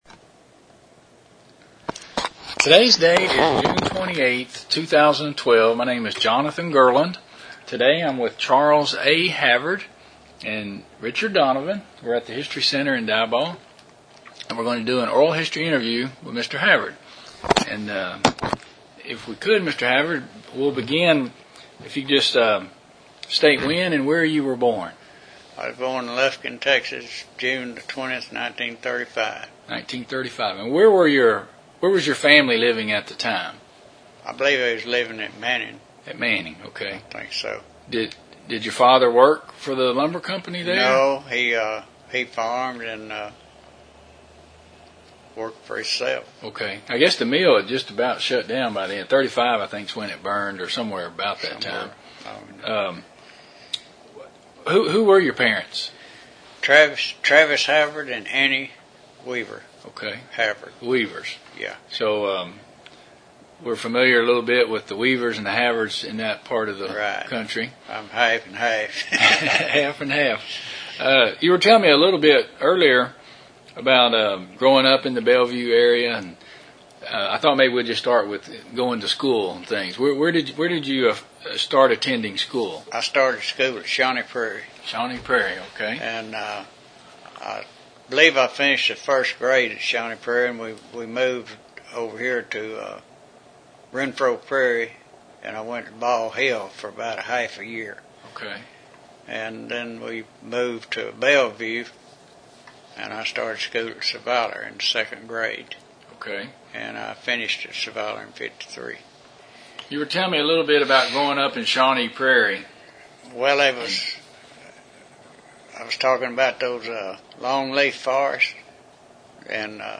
Interview 253a